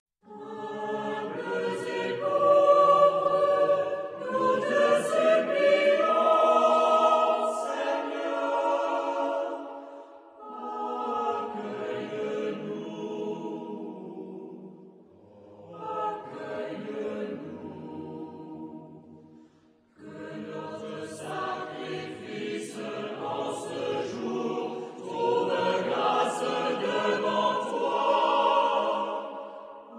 Epoque: 20th century
Genre-Style-Form: Motet ; Sacred
Type of Choir: SATB  (4 mixed voices )
Tonality: phrygian